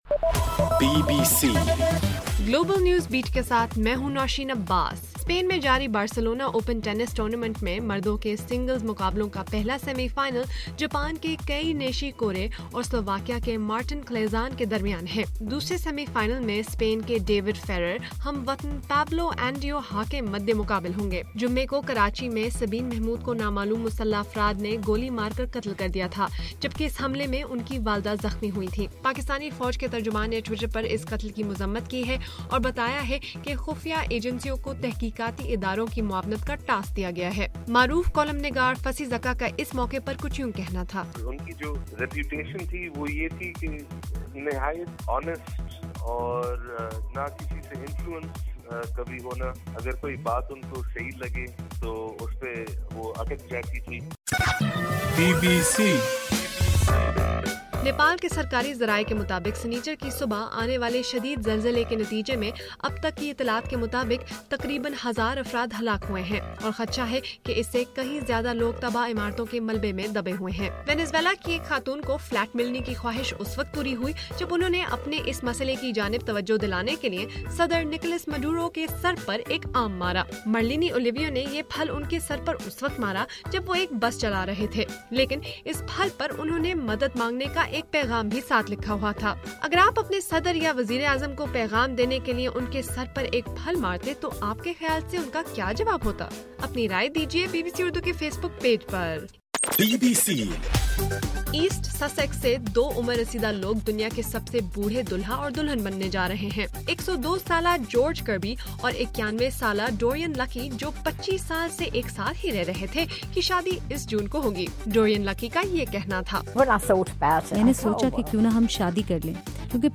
اپریل 25: رات 10 بجے کا گلوبل نیوز بیٹ بُلیٹن